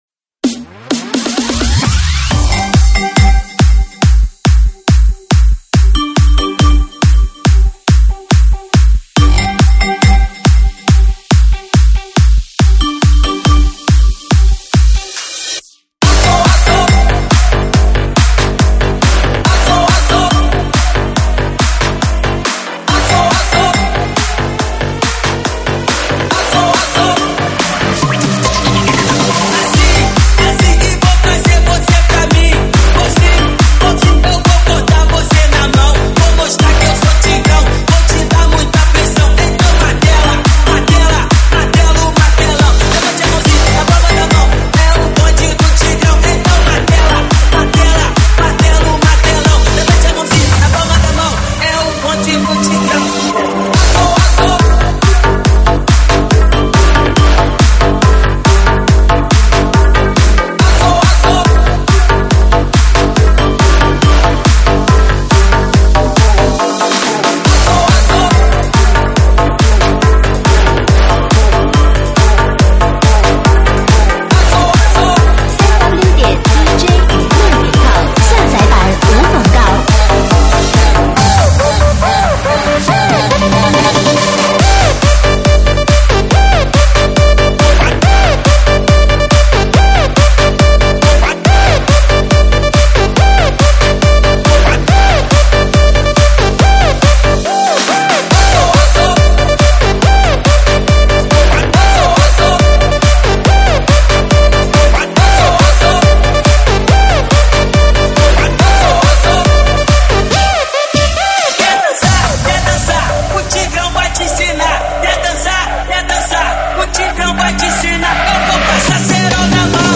中文慢摇